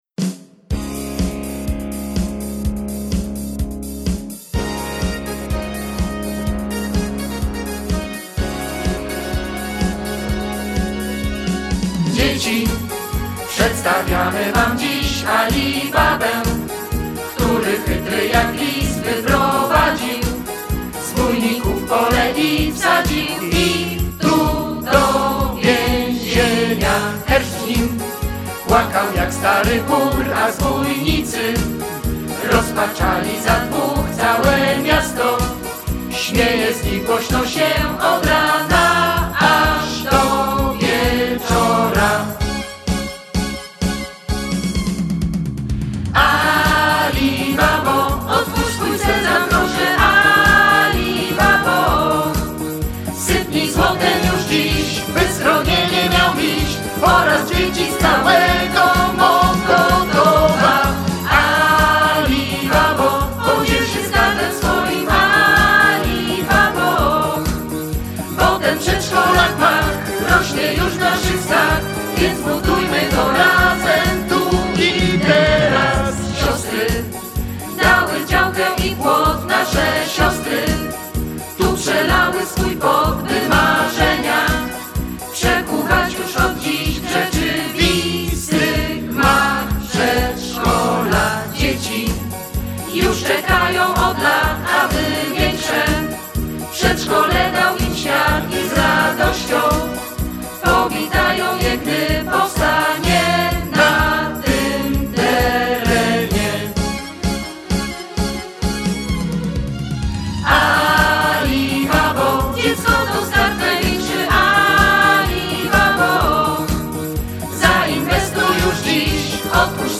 Z okazji Dnia Dziecka rodzice naszych przedszkolaków po raz kolejny przygotowali dla nich bajkę-niespodziankę.